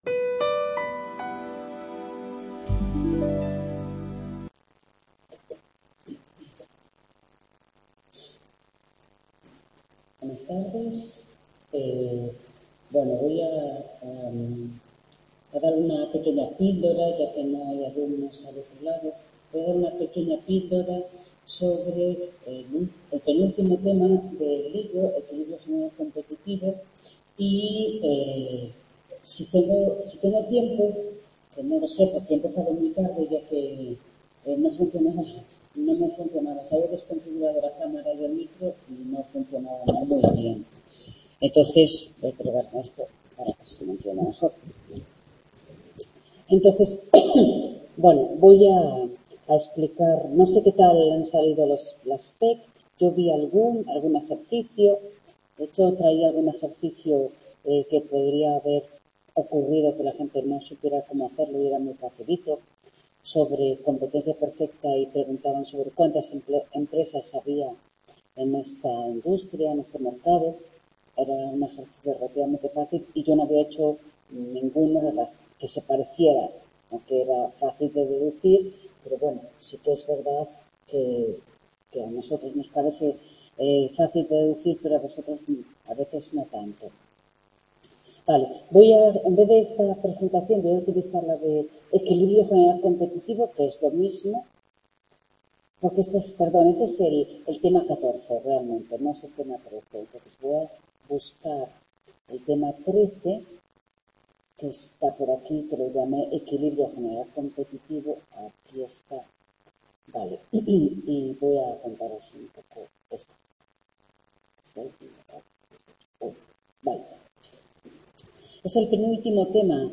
Tutoría de Micoeconomía 2º ADE 12-12-22 | Repositorio Digital